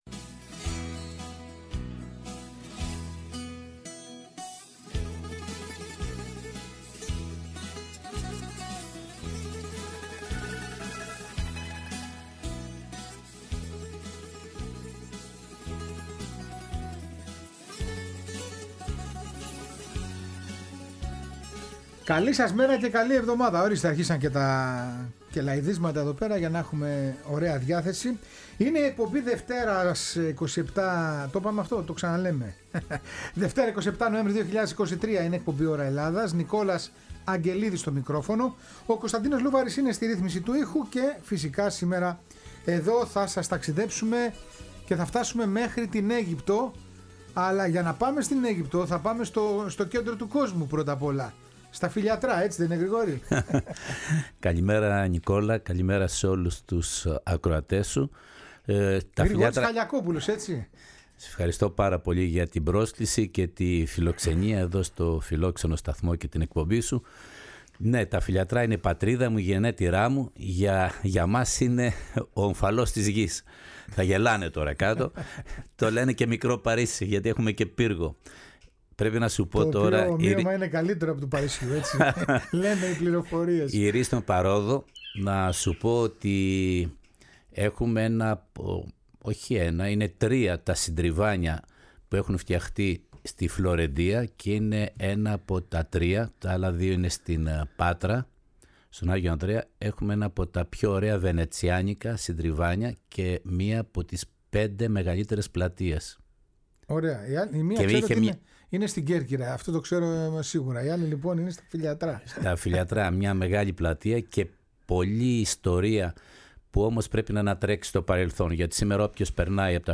Ωρα Ελλαδας ΣΥΝΕΝΤΕΥΞΕΙΣ